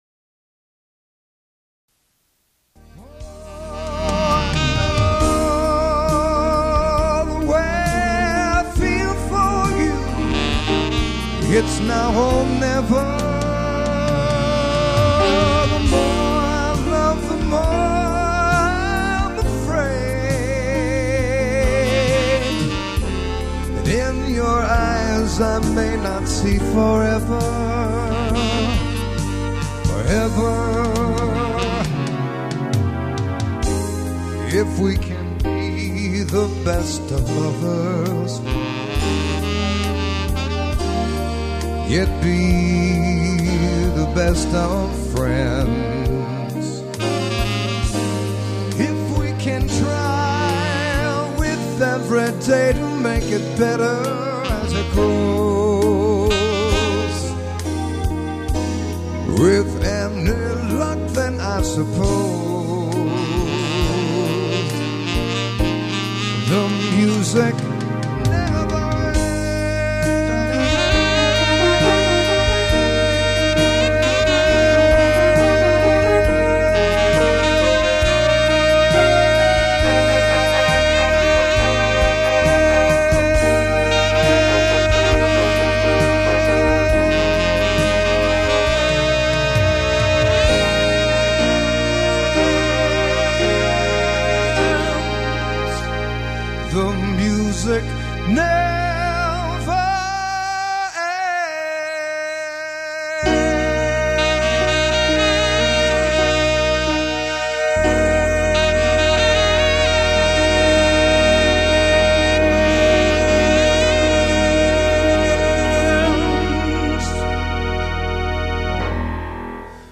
Tenor Sax & Flute
Pop / Swing / Ballad & Standards ....